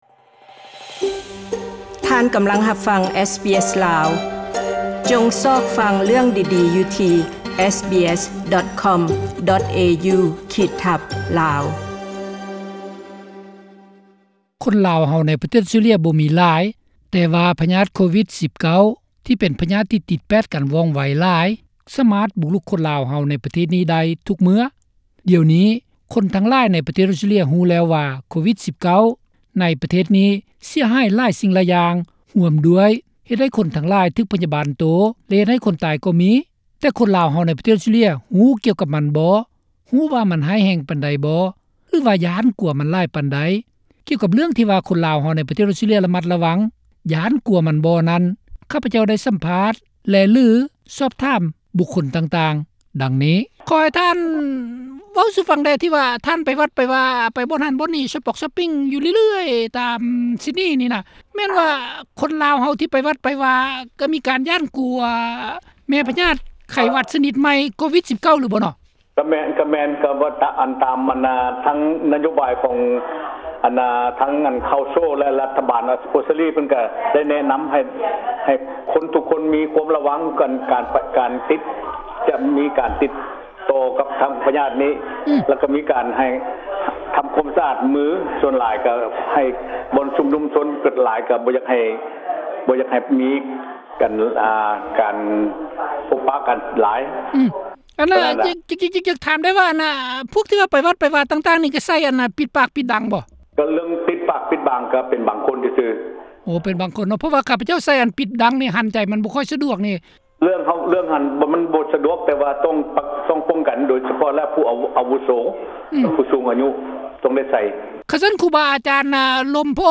ສໍ າ ພ າ ດ ເອົາຄວາມເຫັນຂອງຄົນລາວໃນອອສເຕຼເລັຍກ່ຽວກັບ ໂກວິດ-19